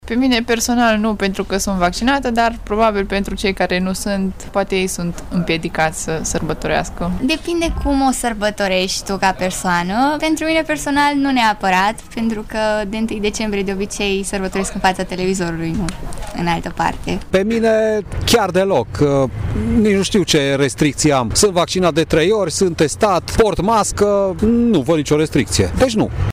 Chiar dacă vor fi restricții, târgumureșenii nu le văd ca pe un impediment: